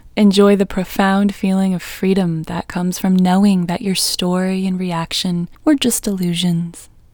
OUT Technique Female English 32